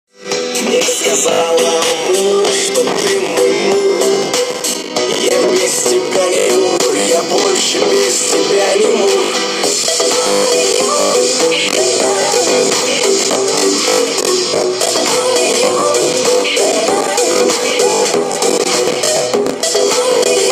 После включения вроде нормально все, но через некоторое время бывает искажение звука/зависание или хз как правильно сказать.
вот записал на телефон, но это еще более мение, бывает такое что как буд,то сатана в комп вселился и что,то сказать мне хочет *